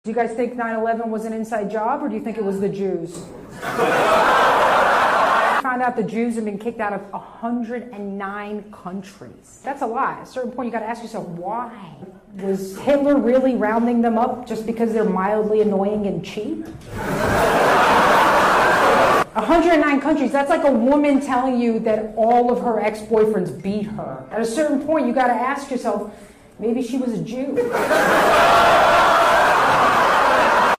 Stand Up Comedienne